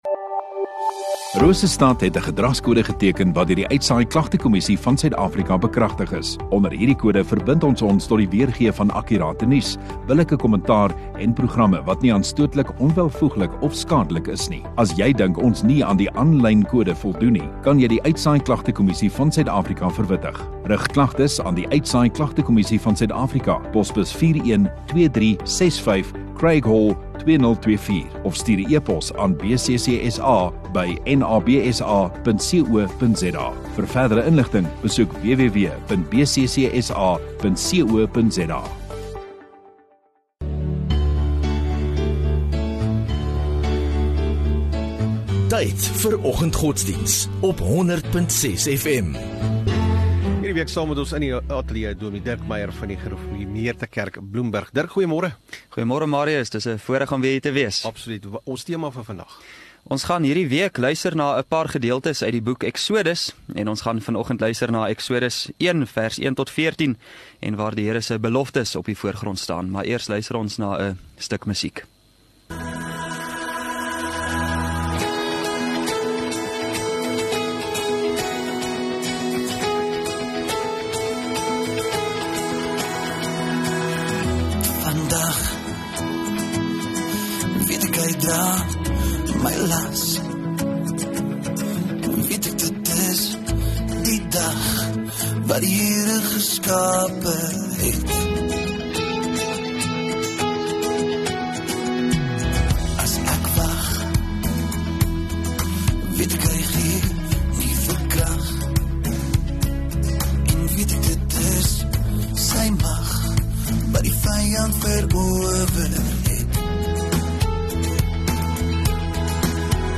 23 Sep Maandag Oggenddiens